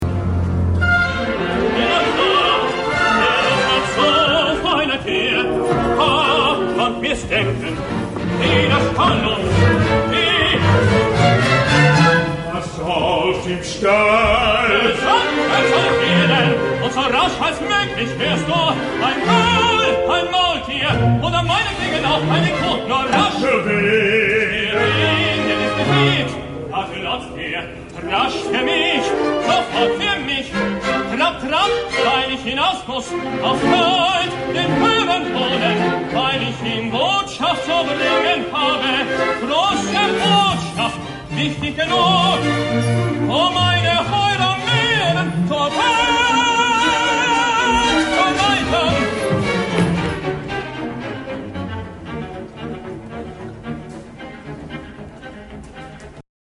Junger Diener
MAILAND
Teatro alla Scala